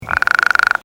The call of the Western Spadefoot is a short loud trill, like a quick snore, lasting less than one second.
Sound  This is a recording of a single advertisement call of a Western Spadefoot recorded at night in Butte County.